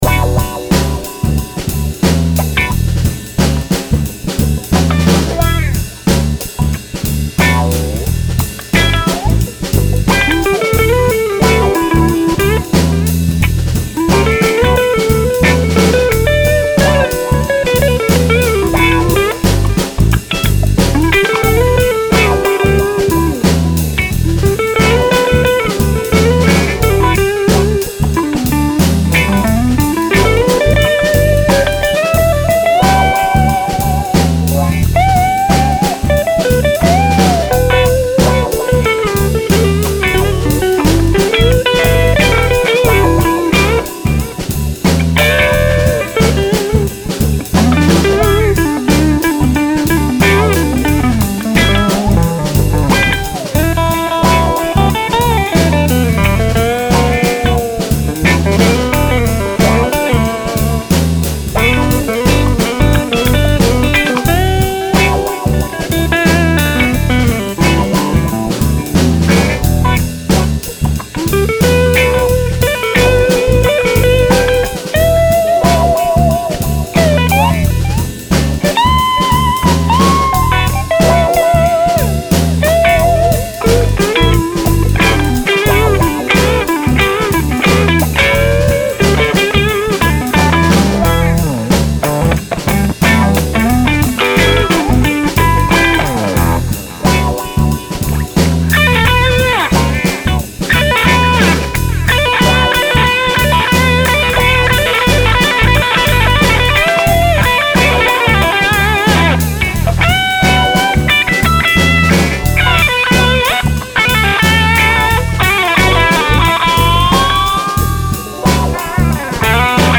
La guitare wha wha, c'est juste le son clair de l'ampli. Jusque 1mn40, je ne joue qu'avec la FB2. Ensuite, j'enclenche l'OCD pour faire hurler.
sympa le sample. j'aime beaucoup le riff a la wah